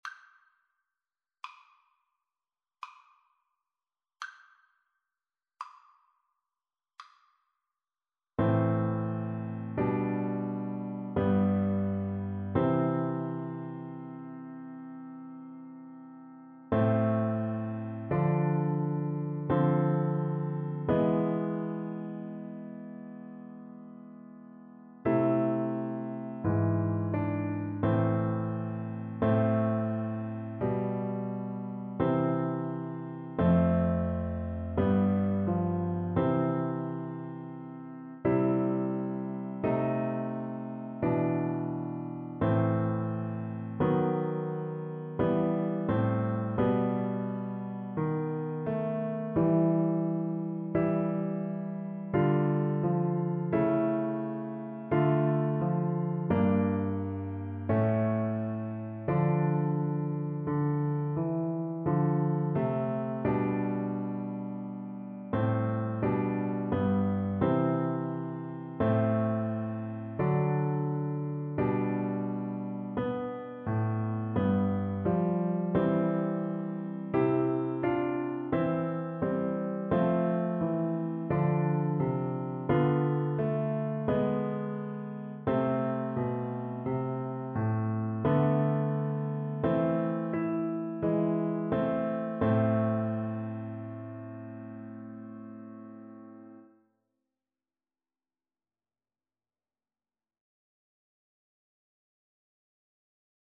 Lento
3/4 (View more 3/4 Music)
Classical (View more Classical Flute Music)